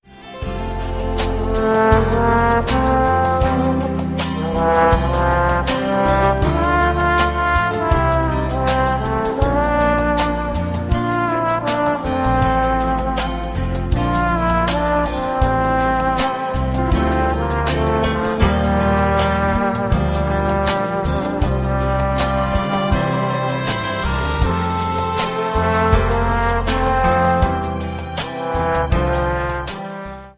• Sachgebiet: Instrumental